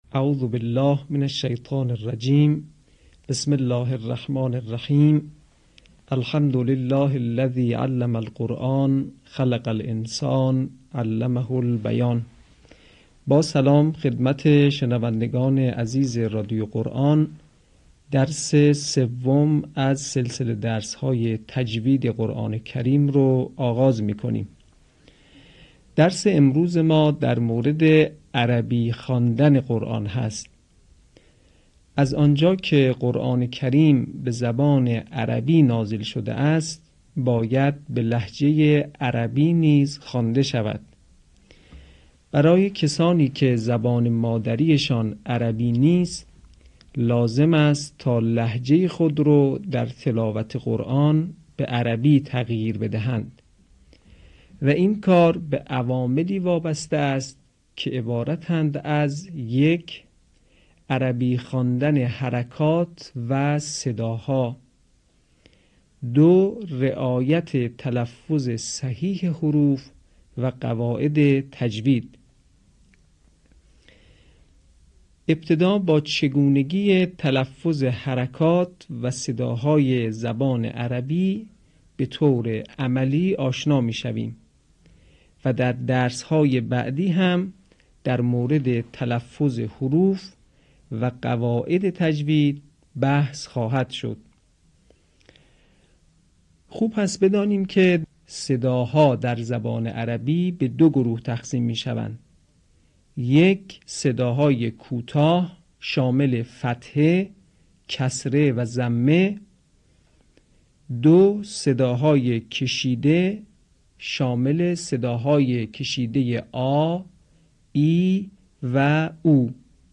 یکی از مهم‌ترین سیاست‌های ایکنا نشر مبانی آموزشی و ارتقای سطح دانش قرائت قرآن مخاطبان گرامی است. به همین منظور مجموعه آموزشی شنیداری (صوتی) قرآنی را گردآوری و برای علاقه‌مندان بازنشر می‌کند.